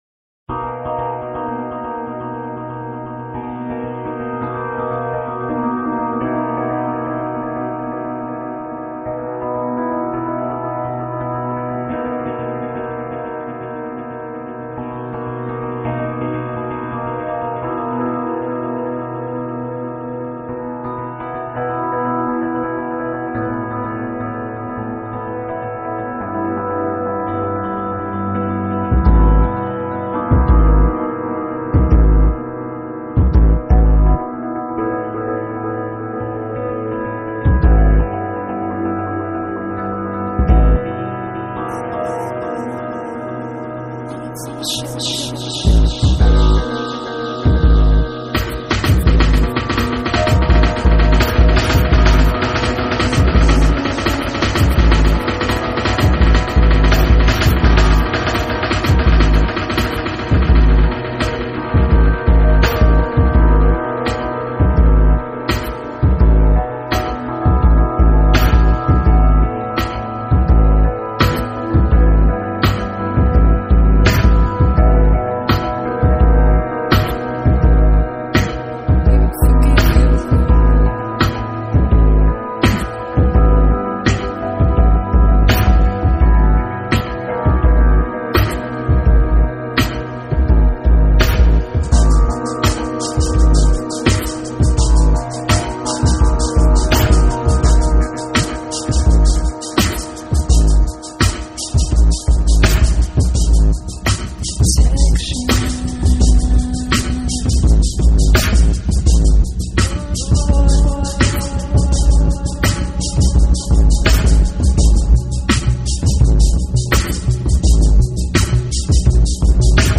Dub, Trip-Hop, Electronic